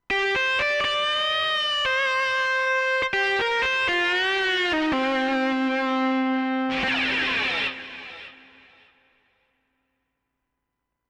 Klanglich findet sich die gesamte Bandbreite an Instrumenten und auch der Synth-Bereich kann sich hören lassen.
Klangbeispiele vom Yamaha Tyros 5
Voices
yamaha_tyros_5_testbericht_voices_rock_hero.mp3